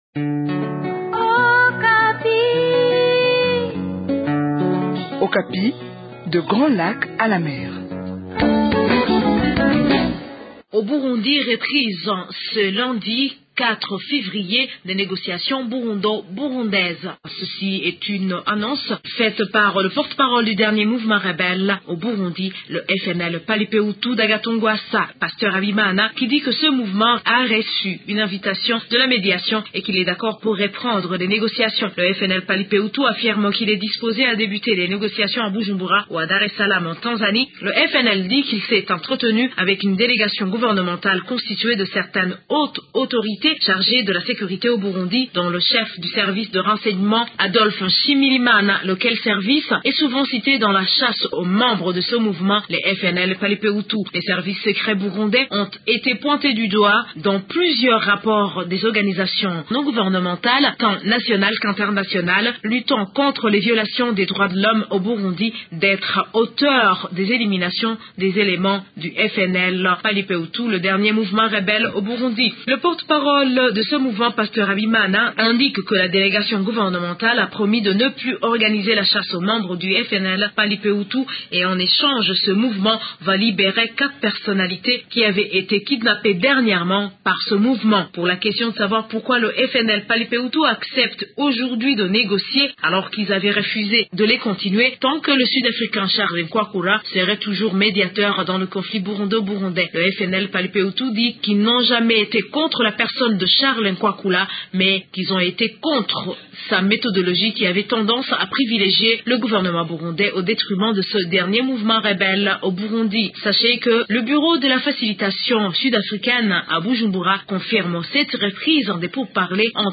Depuis Bujumbura, une correspondance